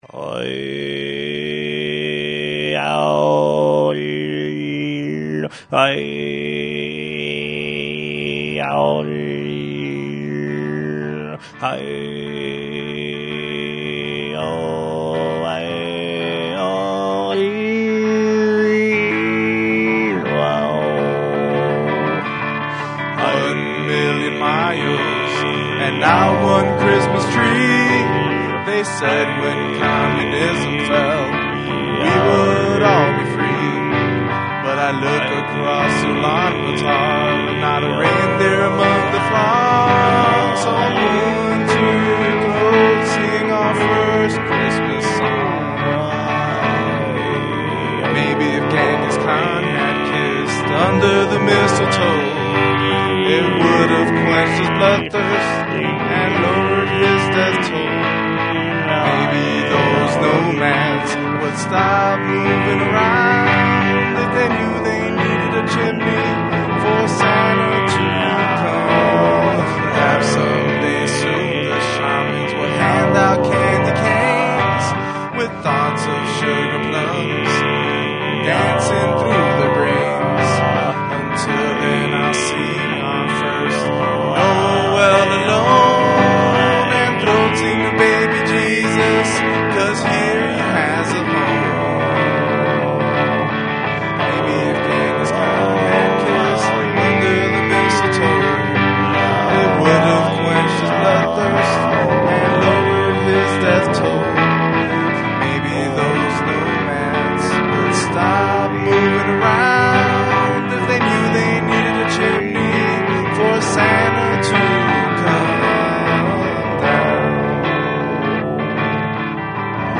I wrote the music and did the throat singing --- lyrics